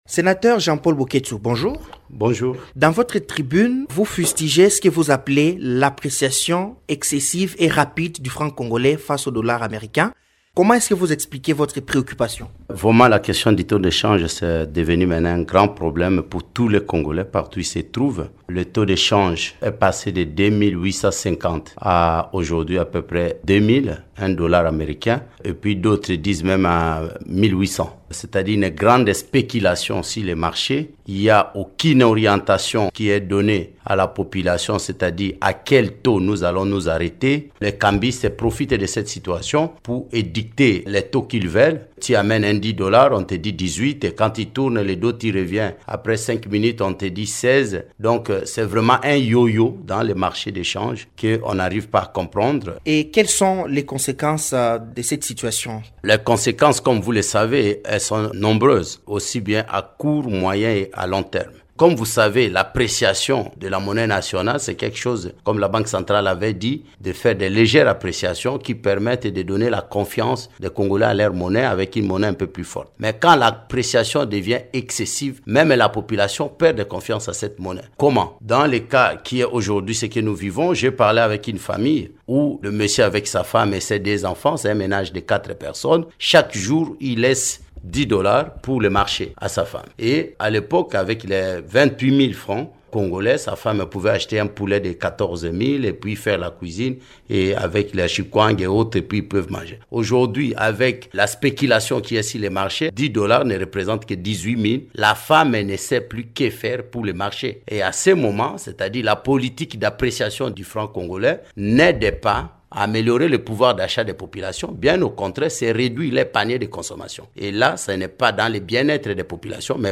Dans une interview donnée le 13 octobre 2025 à Radio Okapi, cet économiste élu de la province de l’Équateur a mis en garde contre les conséquences négatives d’une spéculation excessive sur le taux de change, qui affecte le budget de l’État et la vie quotidienne de la population.
Sénateur Jean-Paul Boketsu est l’invité de la rédaction.